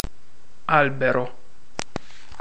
Ääntäminen
UK : IPA : /tɹiː/ UK US : IPA : [tɹiː]